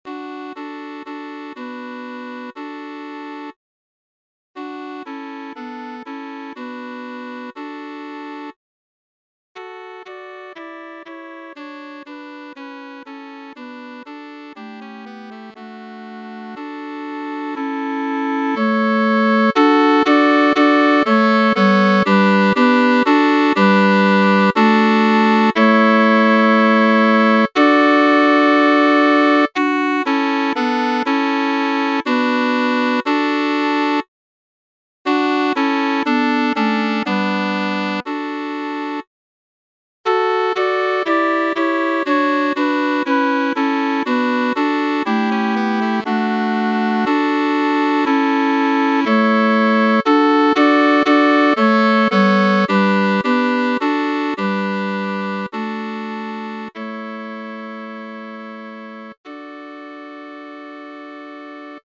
SSAA (4 voices women) ; Choral score.
Tonality: D major